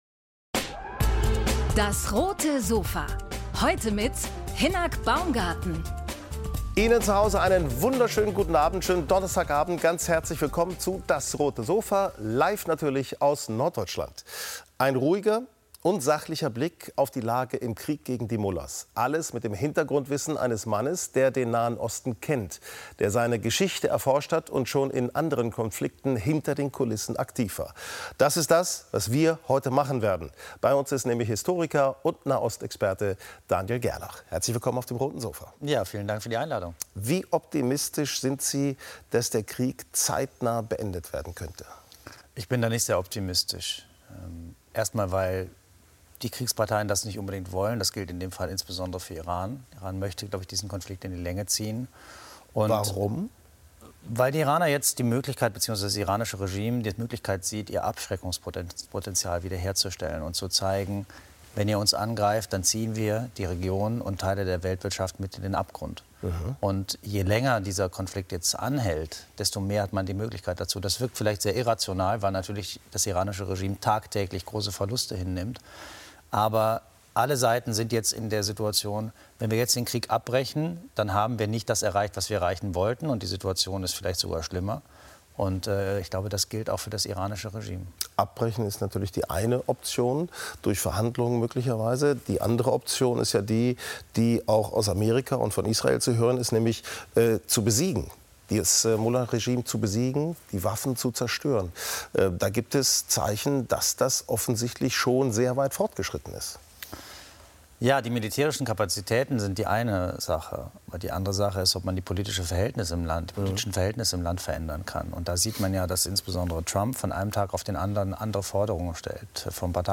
DAS! - täglich ein Interview